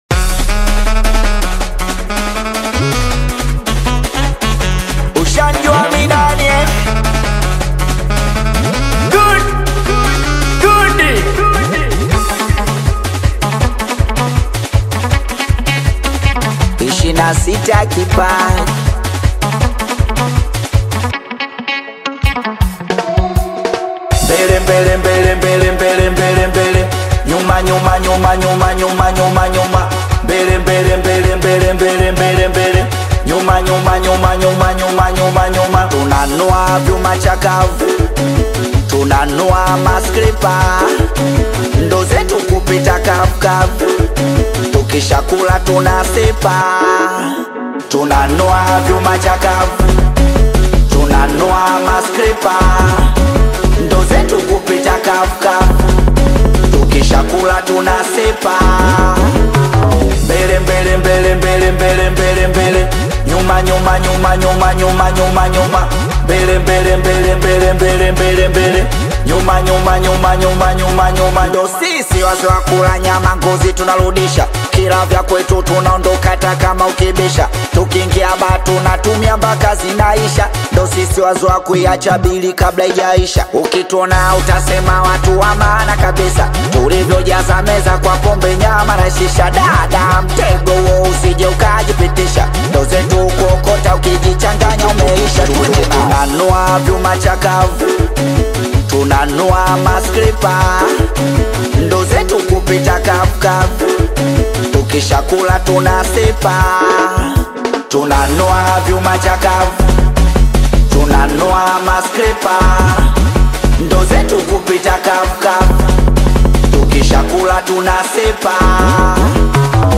SINGELI MUSIC